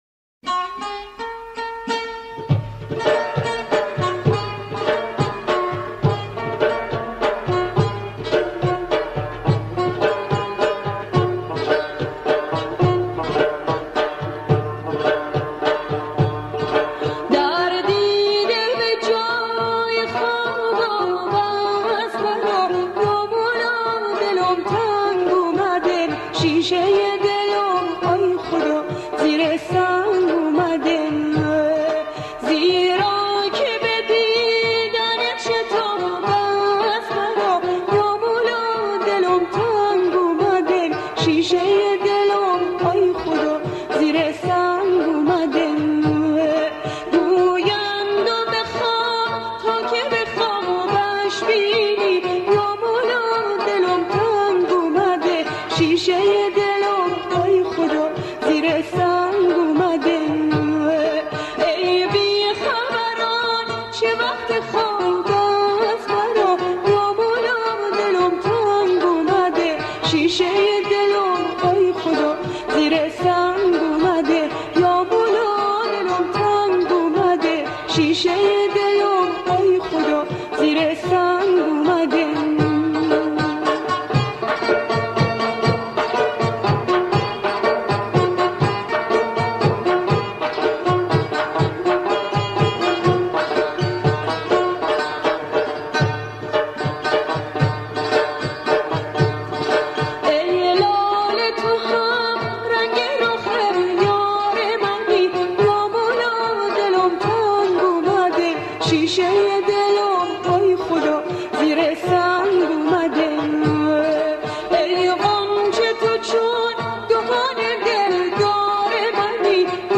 اهنگ دلنواز یا مولا دلم تنگ اومده با صدای زن